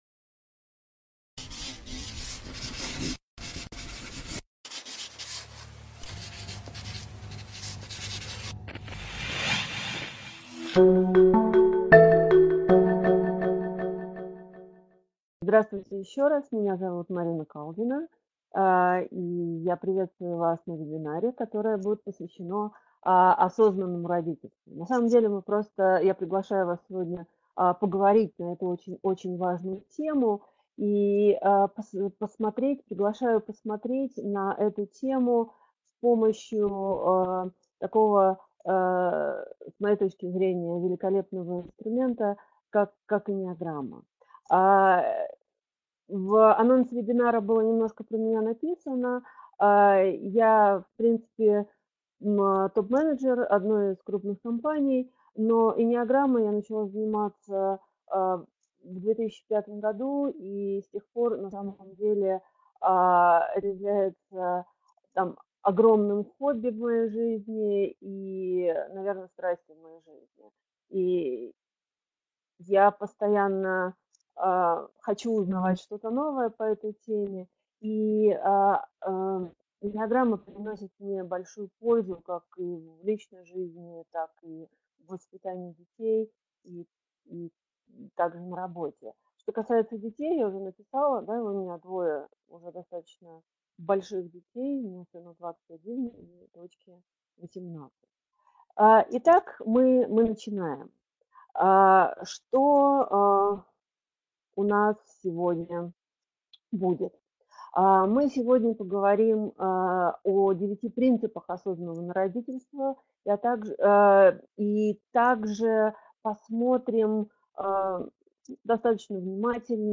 Аудиокнига Эннеаграмма и осознанное родительство | Библиотека аудиокниг